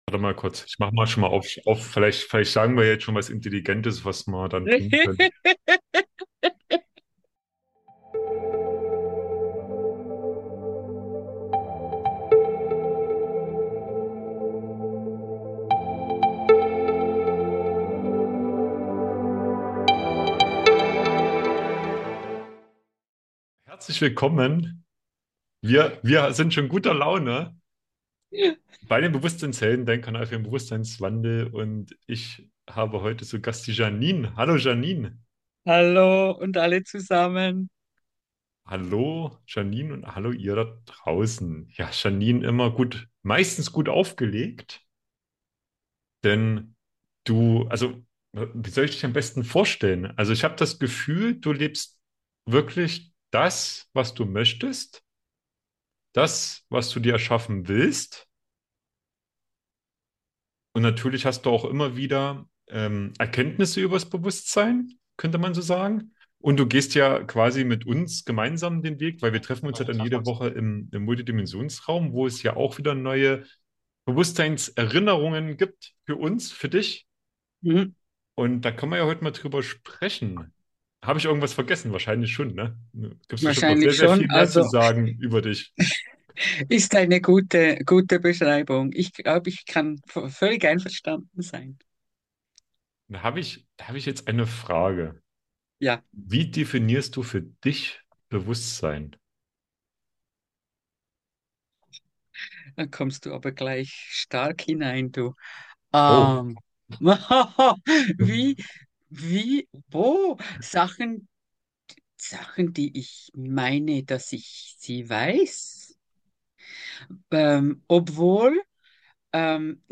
Es ist ein heiterer, aber gleichzeitig tiefgründiger Austausch, der dein Bewusstsein bereichern wird.